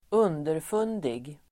Ladda ner uttalet
Folkets service: underfundig underfundig adjektiv, subtle , sly Uttal: [²'un:derfun:dig] Böjningar: underfundigt, underfundiga Synonymer: finurlig, slug Definition: dubbelbottnad (på ett roligt sätt)
underfundig.mp3